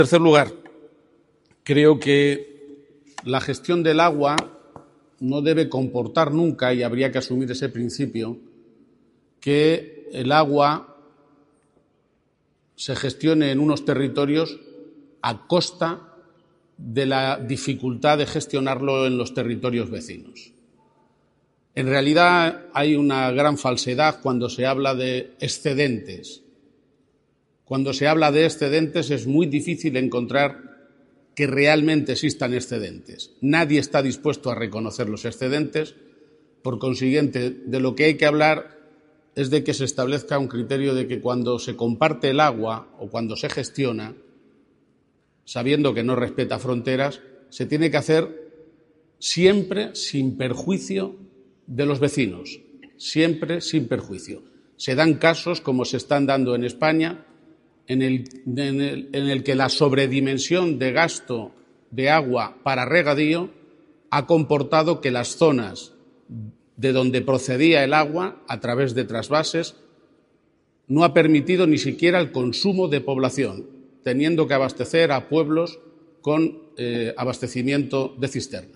GARCÍA-PAGE INTERVENCIÓN ARLEM_SOBREEXPLOTACIÓN EN ORIGEN
intervencion_garciapage_arlem_sobreexplotacion_en_origen.mp3